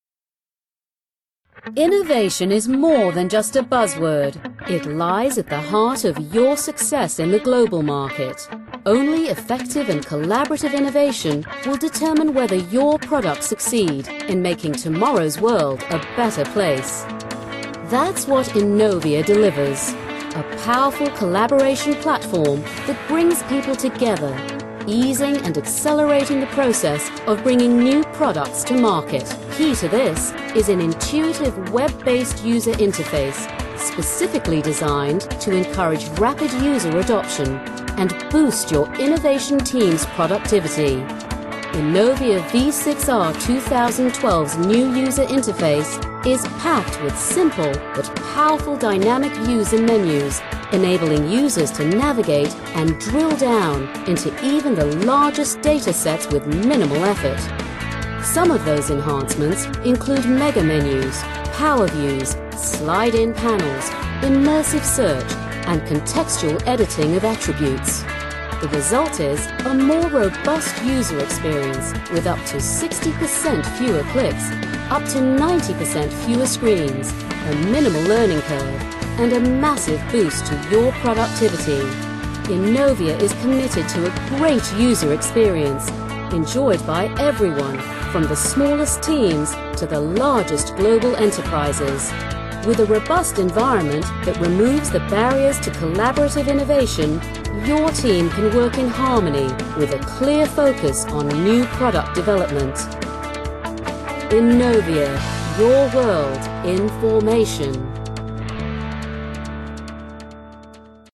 Voice over talent English (British), native speaker. Nonaccent voice. International global vibe.
Sprechprobe: Industrie (Muttersprache):